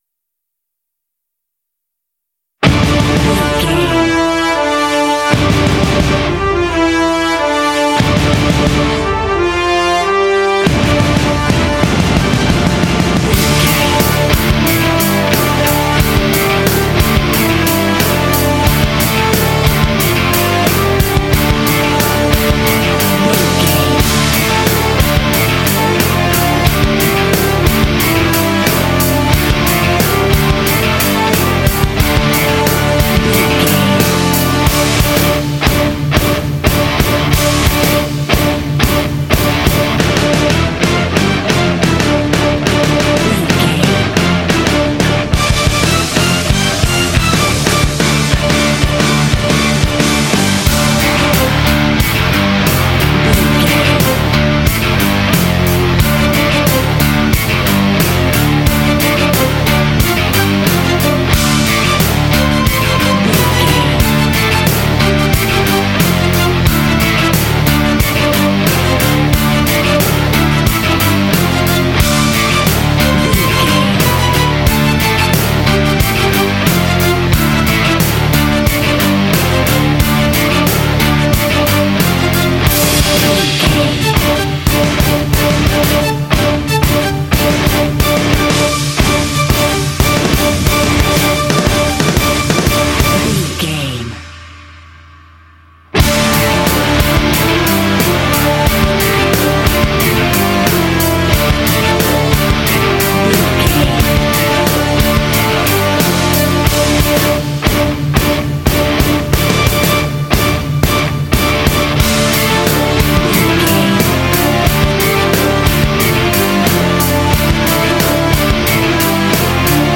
Aeolian/Minor
D
hard
intense
tension
groovy
driving
aggressive
drums
electric guitar
bass guitar
strings
brass
symphonic rock